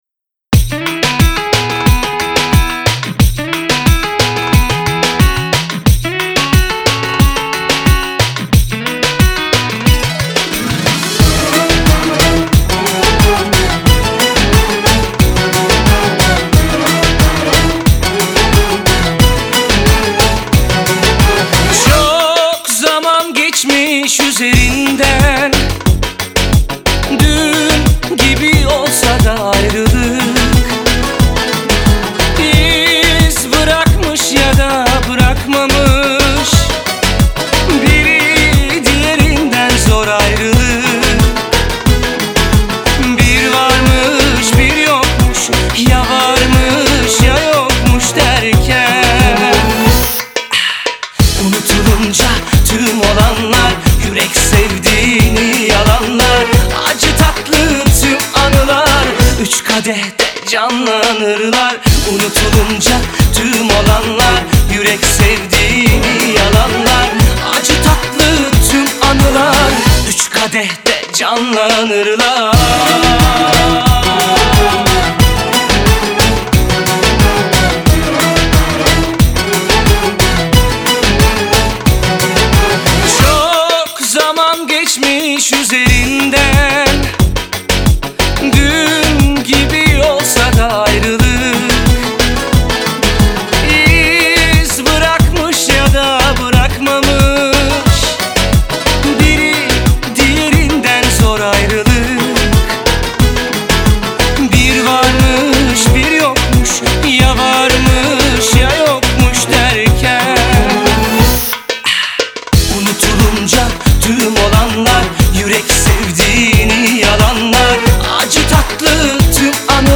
Трек размещён в разделе Турецкая музыка / Поп / 2022.